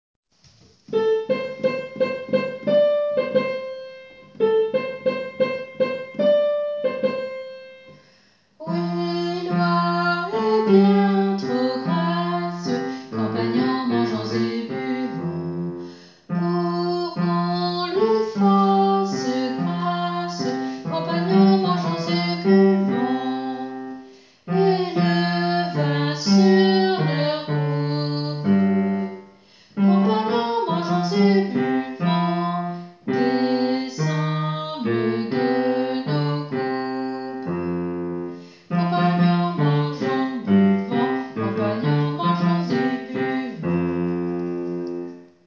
Basses :